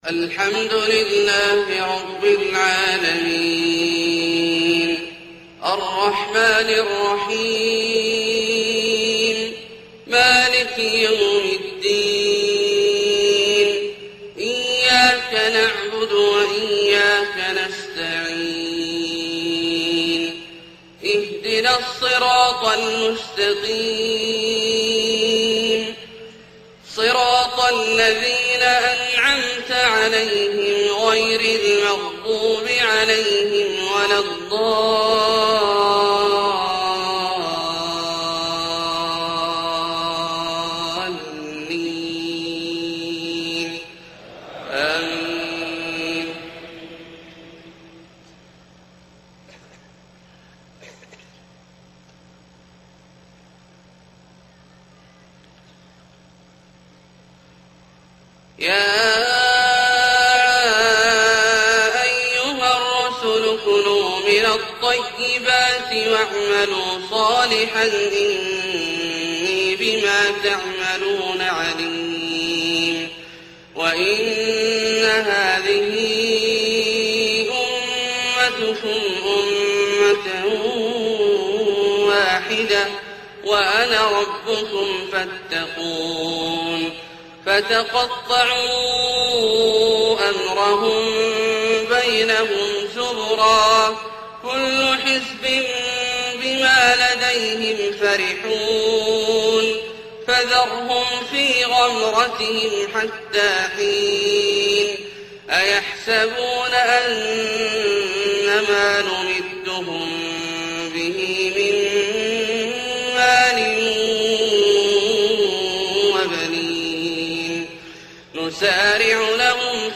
فجر 1-8-1429 من سورة المؤمنون {51-92} > ١٤٢٩ هـ > الفروض - تلاوات عبدالله الجهني